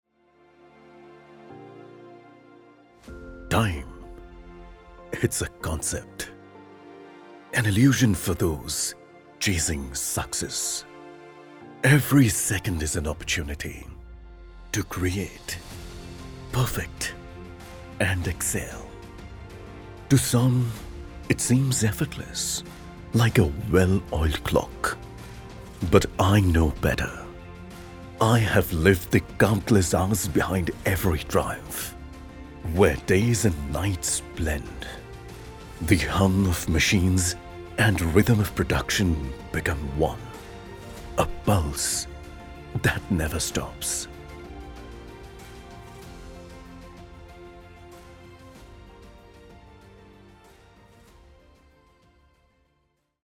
Univeral English Accent, Indian Accent
Whenever you need a deep, baritone, sonorous voice to show your product to the world, you can get all of it from my voice.
English Story Telling, Baritone.mp3